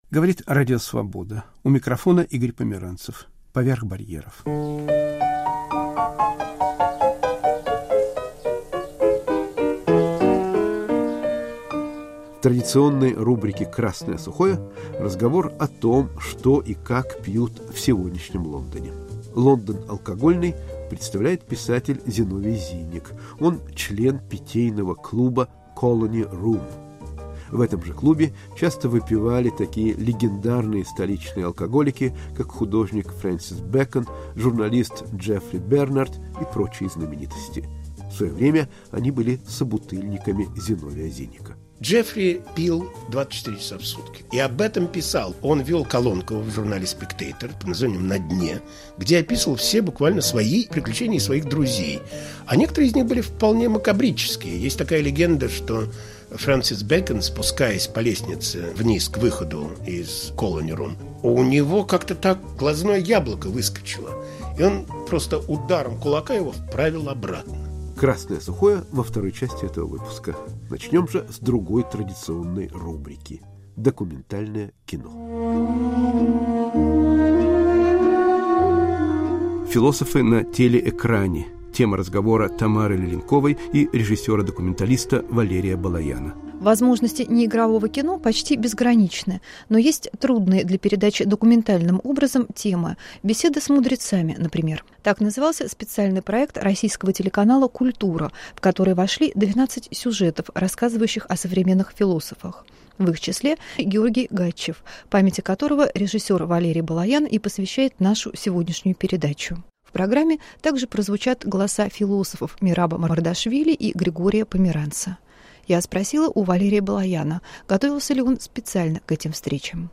Московские музыканты поют песни на идише, иврите, русском и ладино *** Что и как пьют в Лондоне